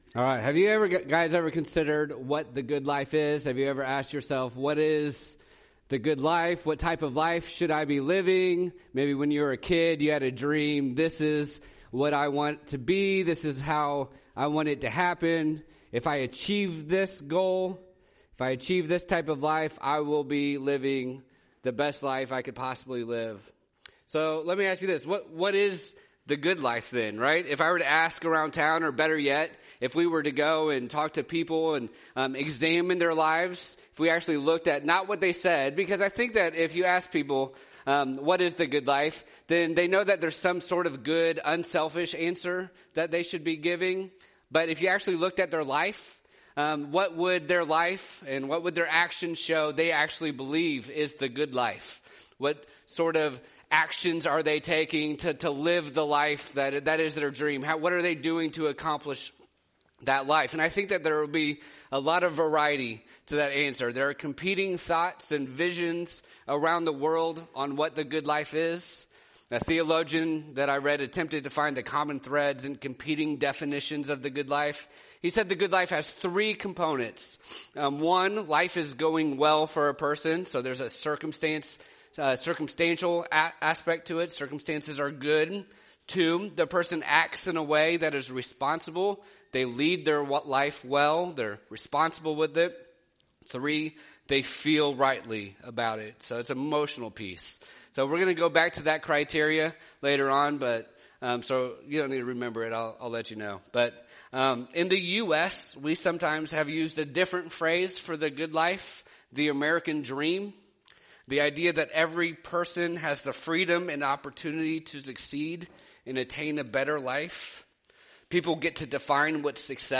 Sermons | Lone Jack Baptist Church